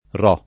vai all'elenco alfabetico delle voci ingrandisci il carattere 100% rimpicciolisci il carattere stampa invia tramite posta elettronica codividi su Facebook Ro [ r 0+ ] top. — nome di due paesi in provincia l’uno di Ferrara (anche Ro Ferrarese ), l’altro di Brescia (distinto in Ro di Sotto e Ro di Sopra ) — sim. il cogn.